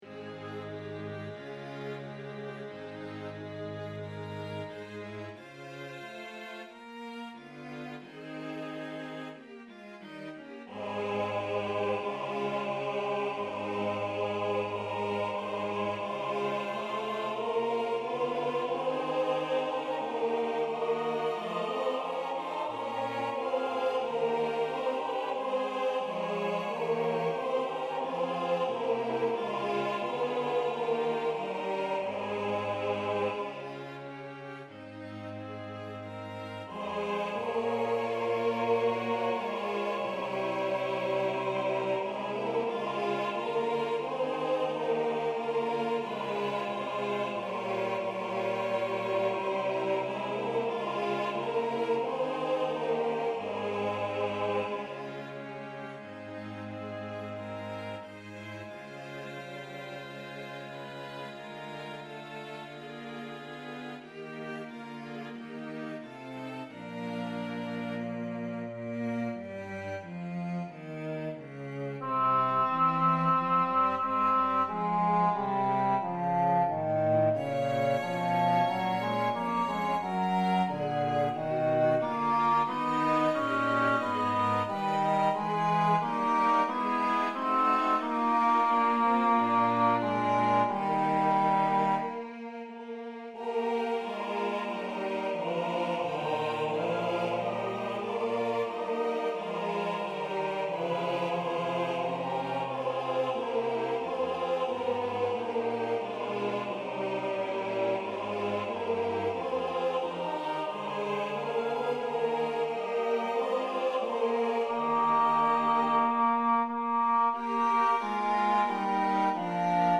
schubert-messe-es-dur-05-benedictus-einstudierung-tenor.mp3